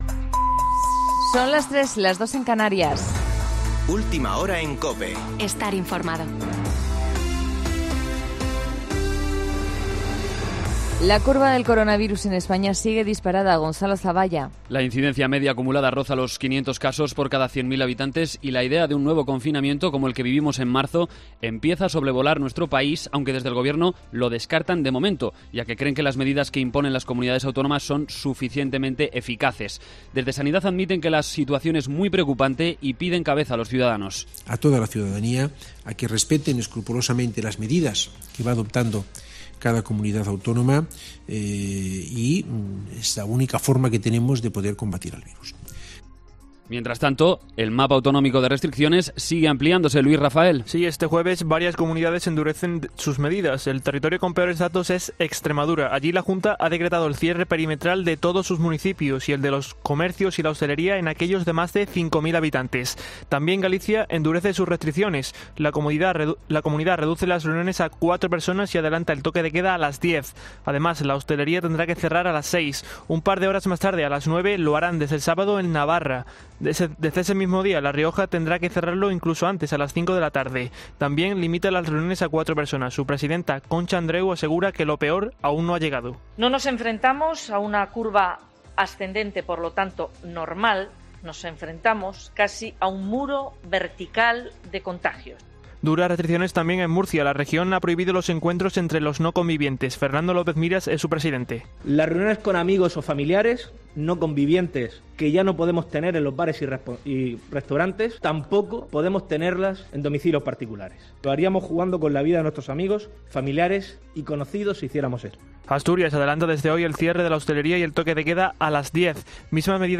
Boletín de noticias COPE del 14 de enero de 2020 a las 03.00 horas